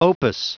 Prononciation du mot opus en anglais (fichier audio)
Prononciation du mot : opus